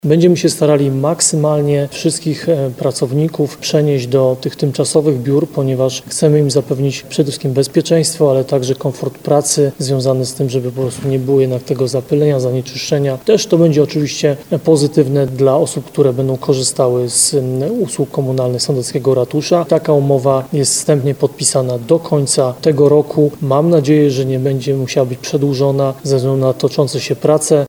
Mam nadzieję, że nie będzie musiała być przedłużona ze względu na toczące się prace – mówił prezydent Nowego Sącza, Ludomir Handzel.